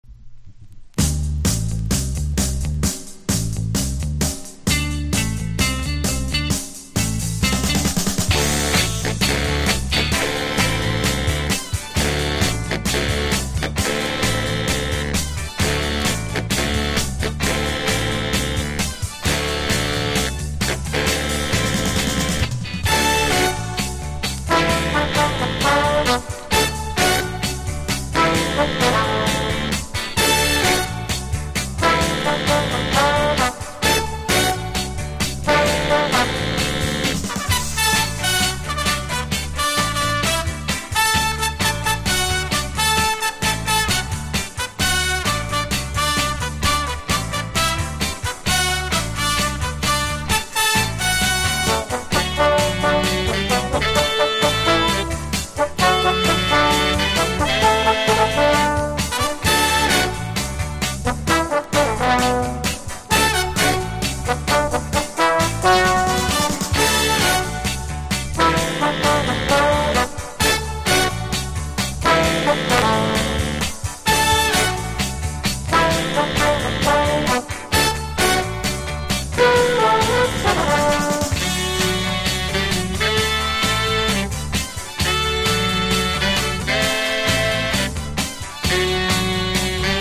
BIGBAND / SWING